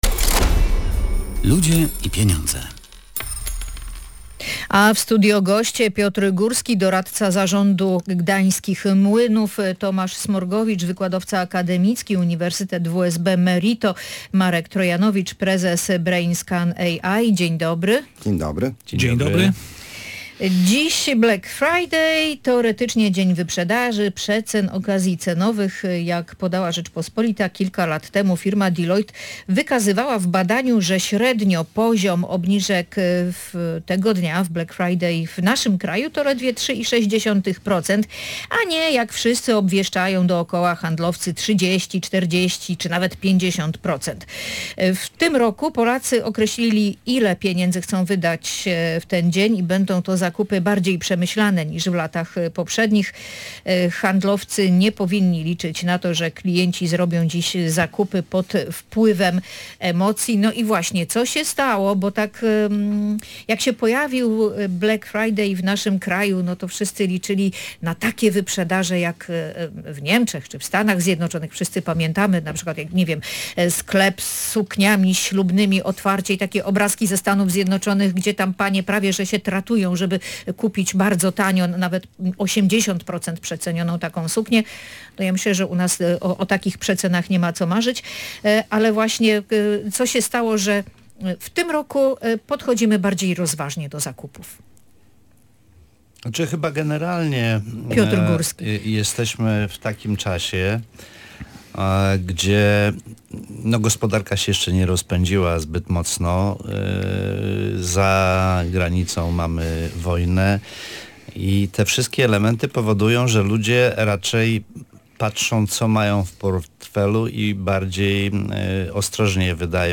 W audycji „Ludzie i Pieniądze” wspomnianą sprawę komentowali goście